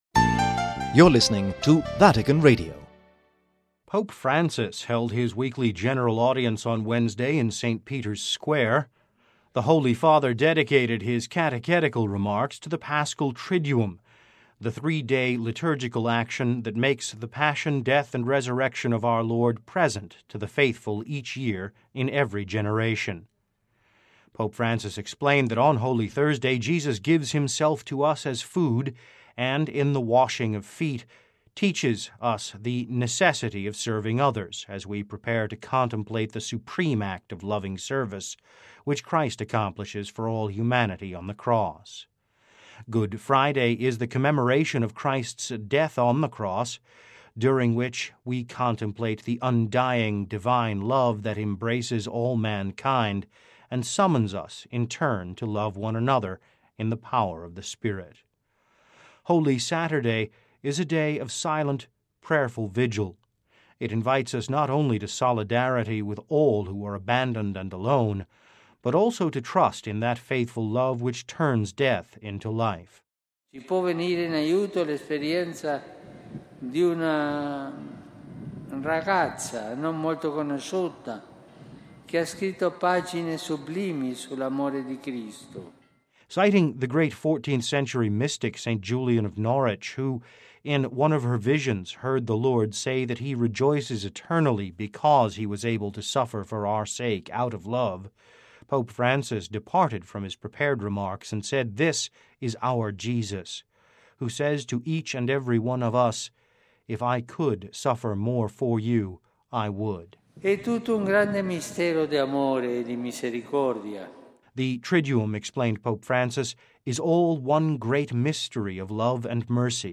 (Vatican Radio) Pope Francis held his weekly General Audience on Wednesday in St. Peter’s Square.